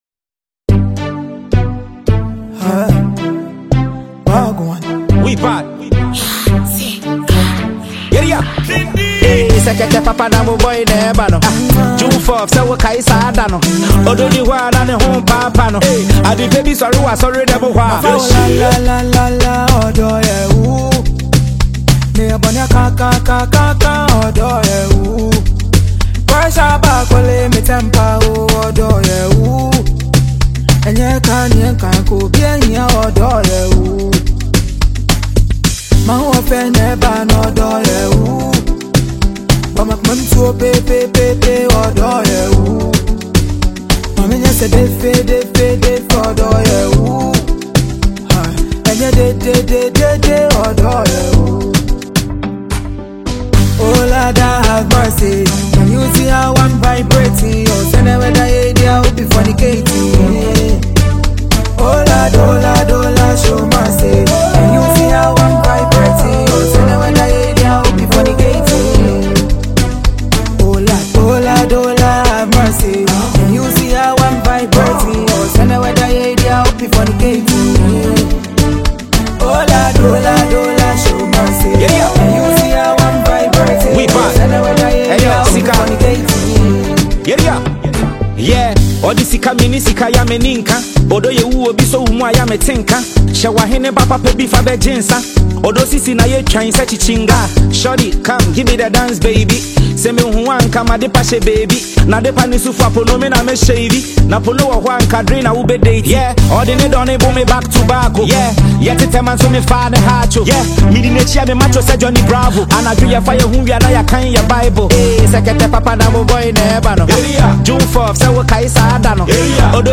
With its infectious beat and emotive lyrics